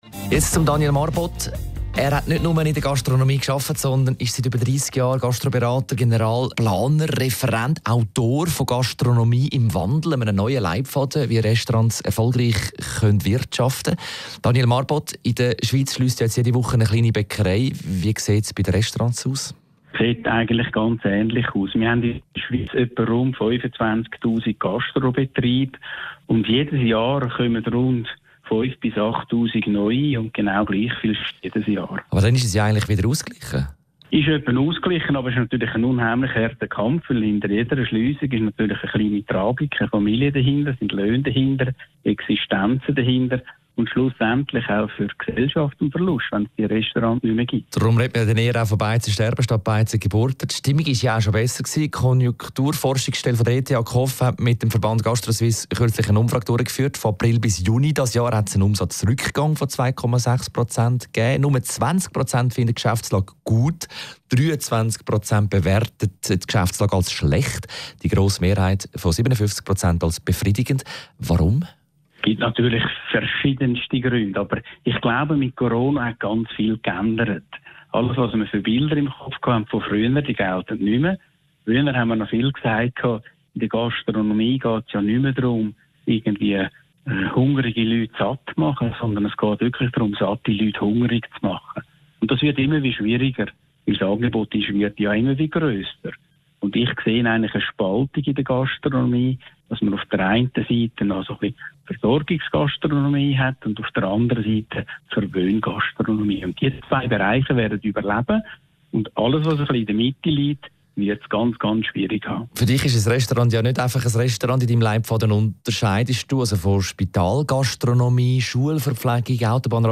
In diesem Radiointerview